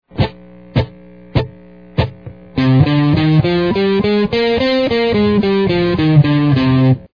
Scales and Modes on the Guitar
C-Minor.mp3